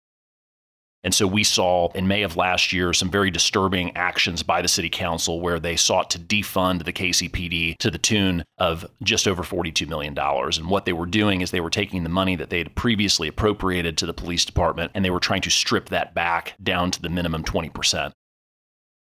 2. Senator Luetkemeyer adds Senate Joint Resolution 38, which would — upon voter approval — modify constitutional provisions relating to funding for a police force established by a state board of police commissioners, is now in the Missouri House of Representatives.